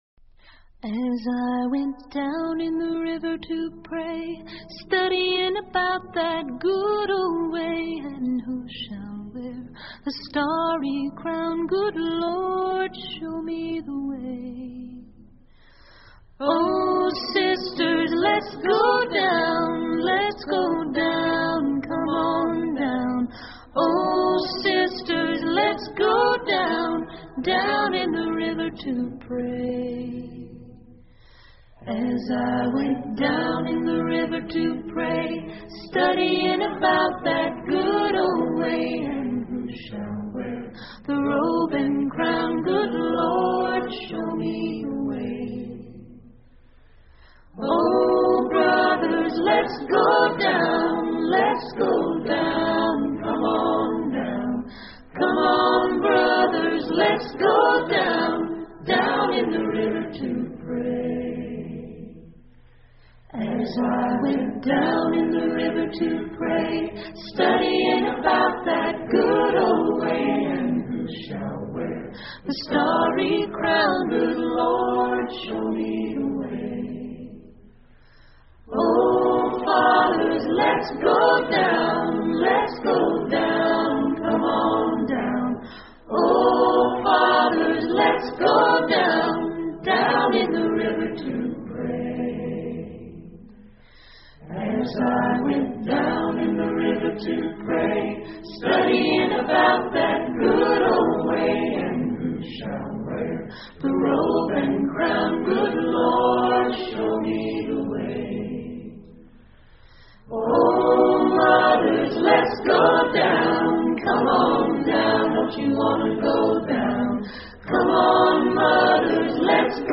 英文乡村歌曲：河中的祈祷 Down The River To Pray 听力文件下载—在线英语听力室